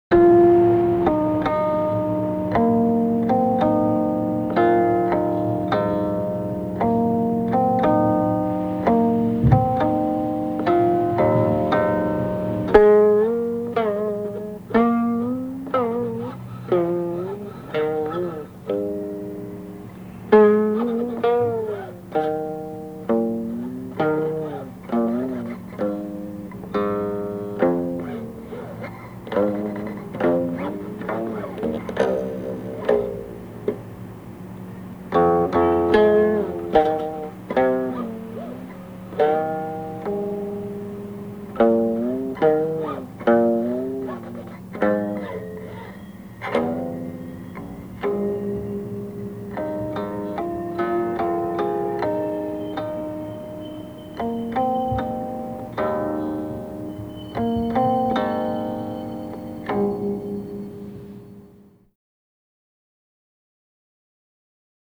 六十年代录音资料